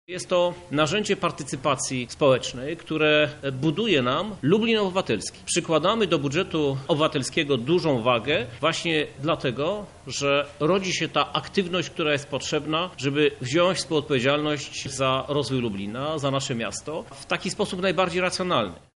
O budżecie mówi prezydent Lublina, Krzysztof Żuk.